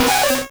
Cri de Nidoran♀ dans Pokémon Rouge et Bleu.